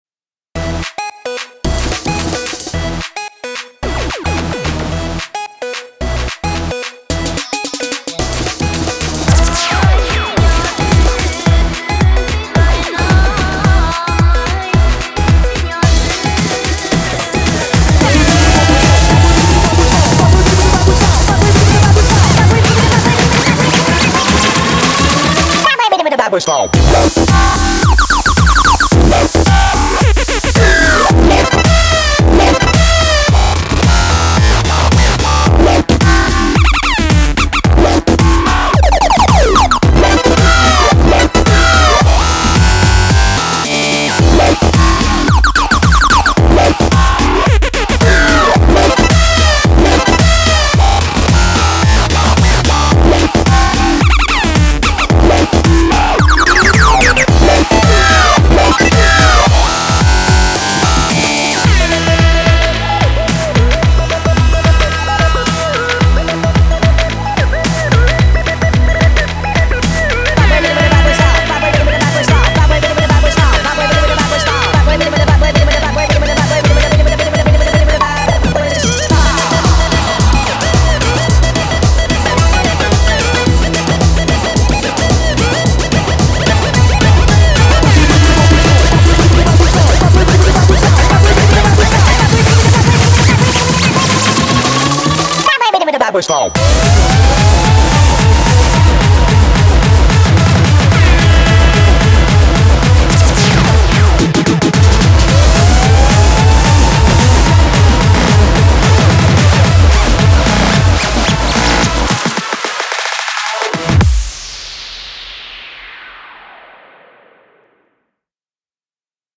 BPM110--1
Audio QualityPerfect (High Quality)